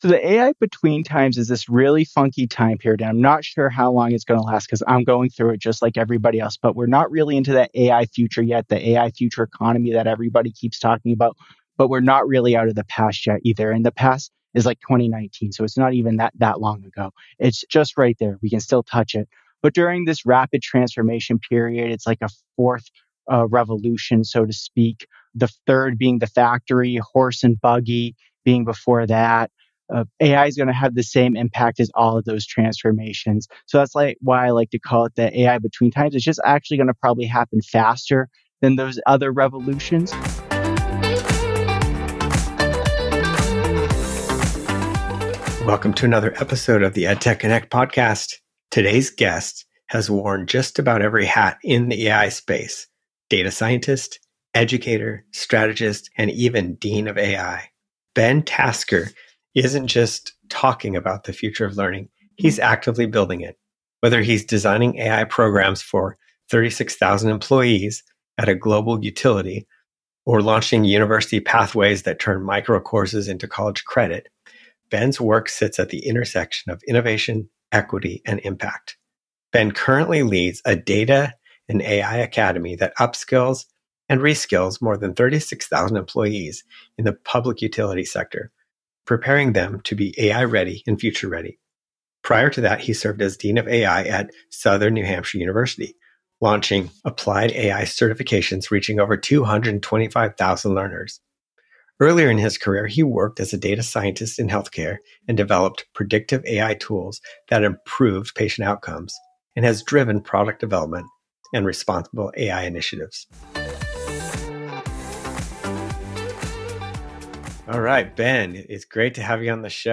Whether you're in higher ed, corporate training, or just thinking about the future of learning, this conversation offers actionable insights and a hopeful vision for an AI-enabled, accessible, and human-centered education system.